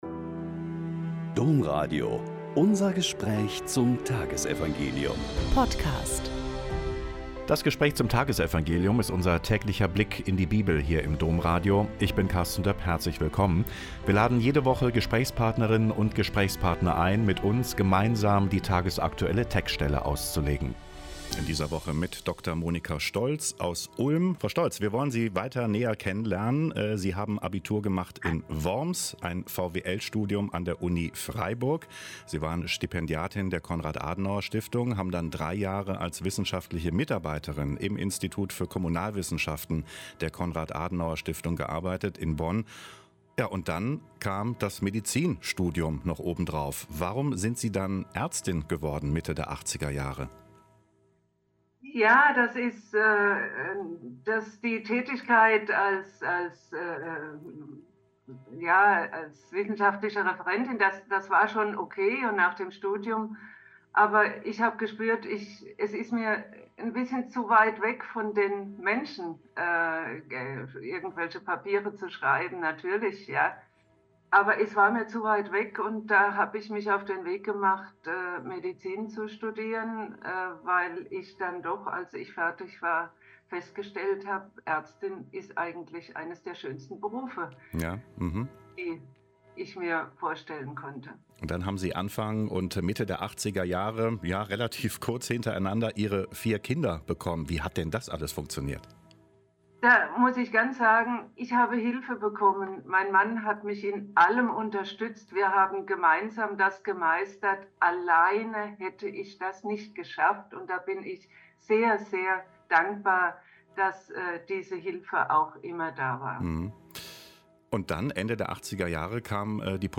Lk 4,38-44 - Gespräch